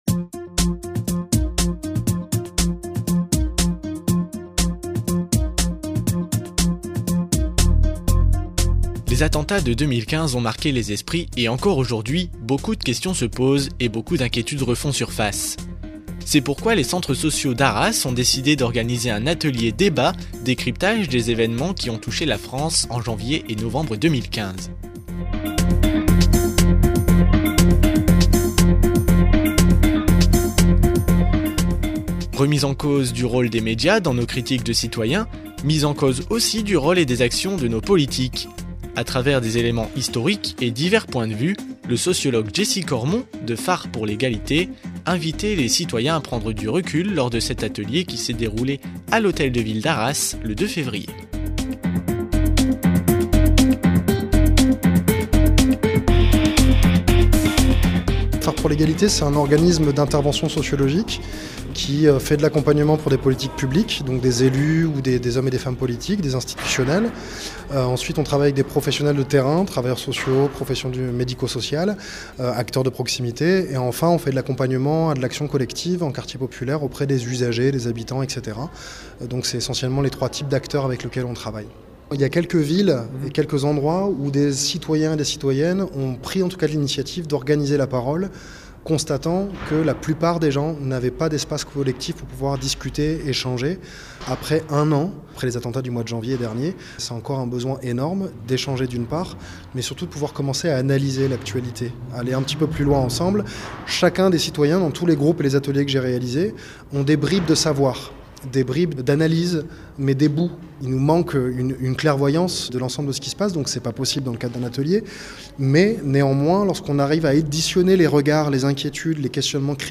Le mardi 2 février, à l’Hôtel de Ville d’Arras, se déroulait un atelier de décryptage et de débat autour des événements tragiques qui ont touché la France en 2015.
Reportage débat arras ok .mp3